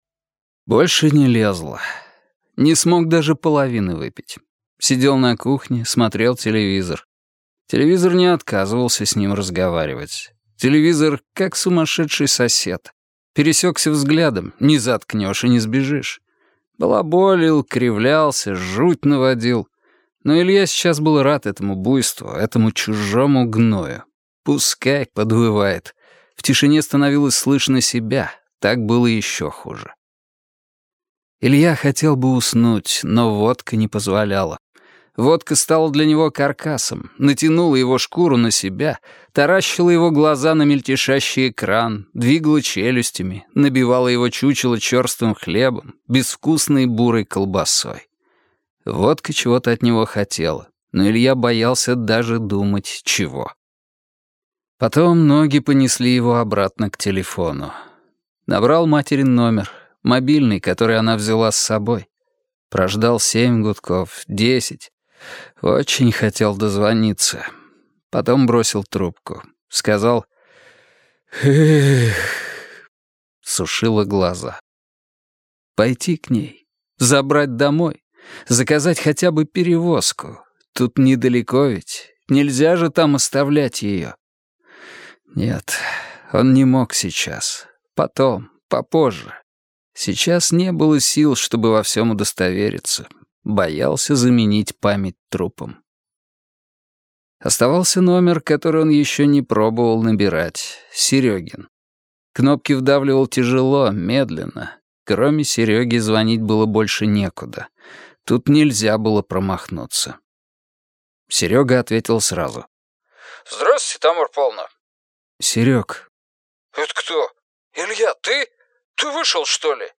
Аудиокнига Текст - купить, скачать и слушать онлайн | КнигоПоиск